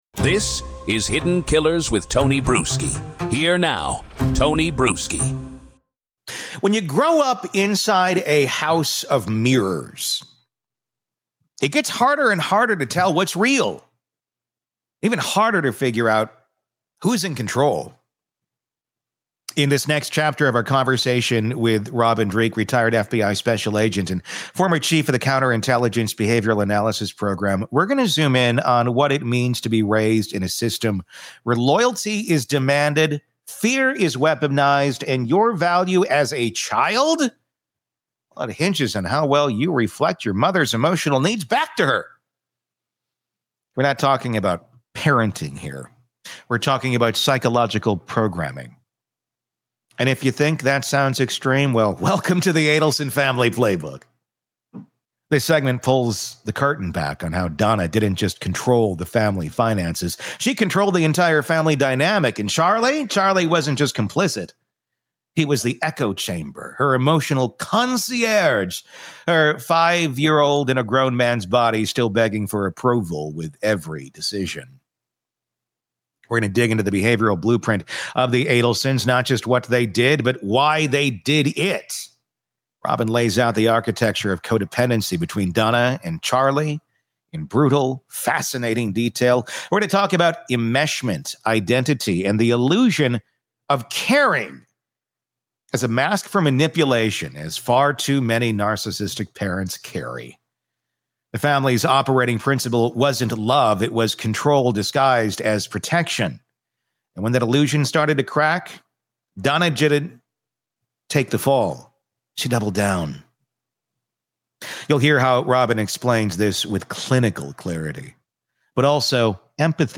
In Part 2 of our conversation